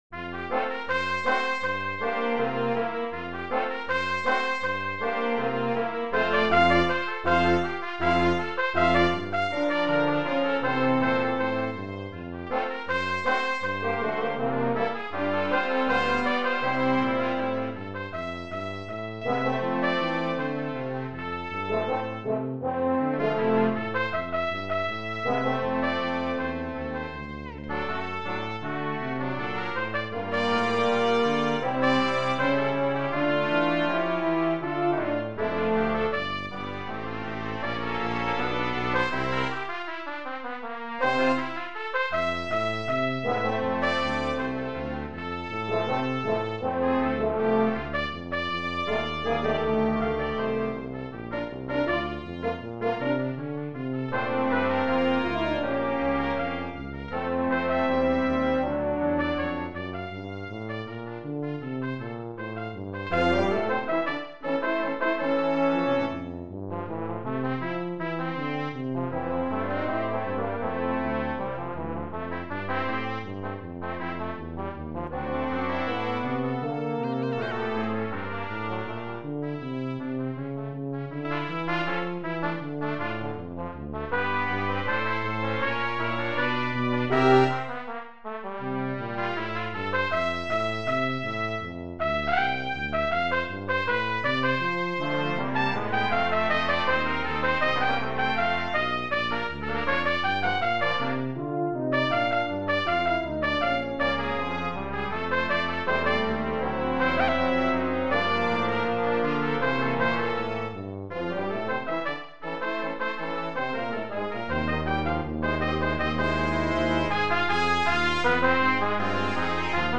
Brass Quintet
trumpet
trombone gets a solo